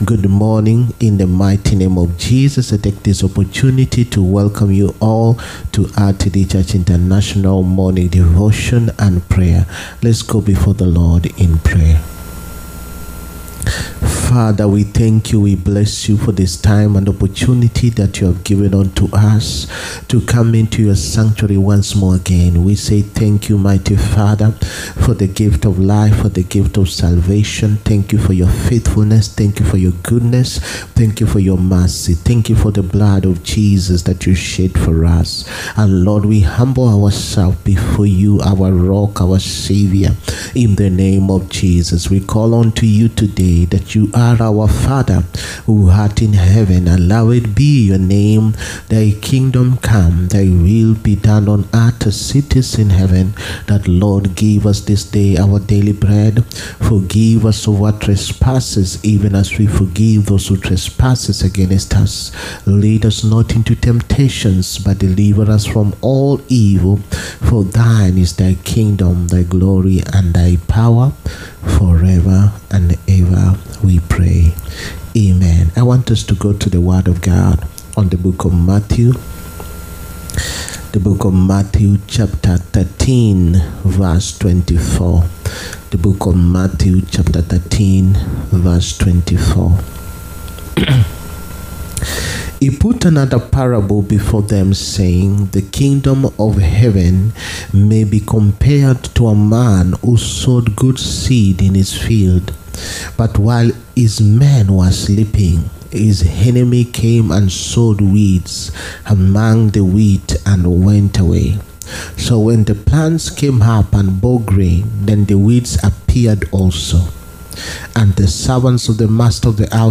MORNING DEVOTION AND PRAYERS. MATHEW CHAPTER 13. PART 6
MORNING-DEVOTION-AND-PRAYERS.-MATHEW-CHAPTER-13.-PART-6.mp3